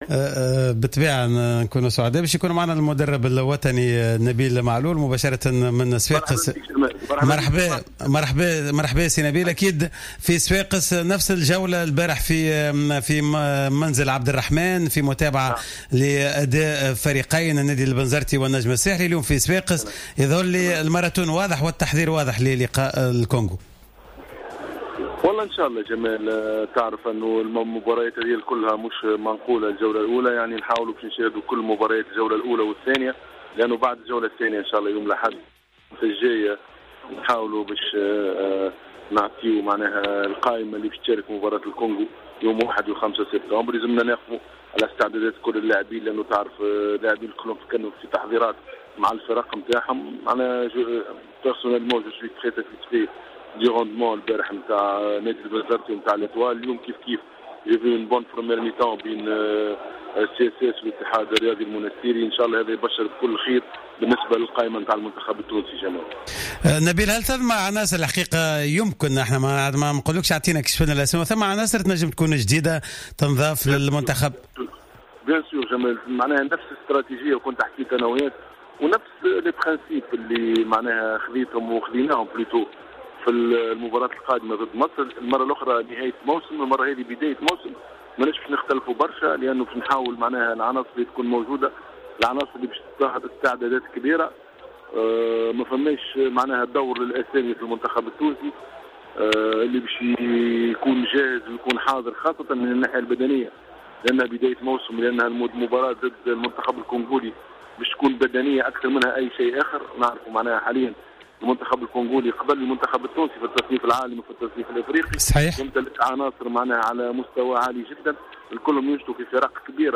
نبيل معلول : مدرب المنتخب الوطني